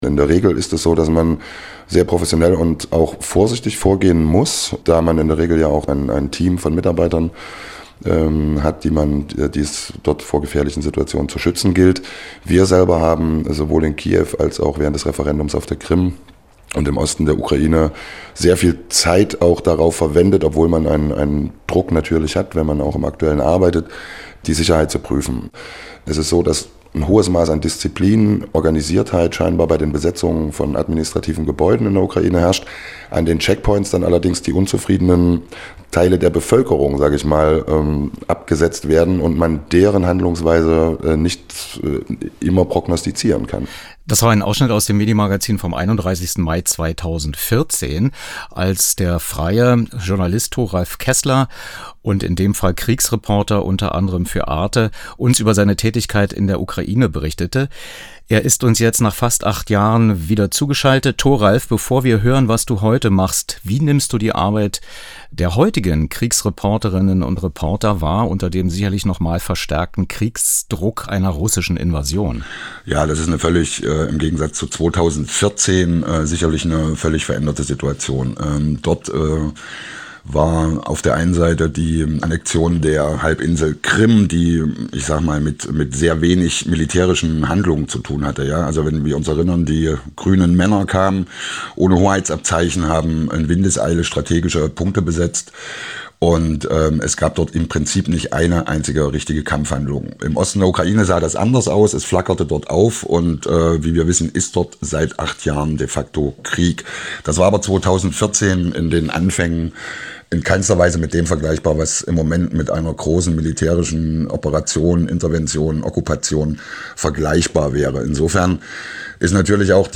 muPRO-Schaltgespräch zum Ukraine-Krieg Russlands 2014/2022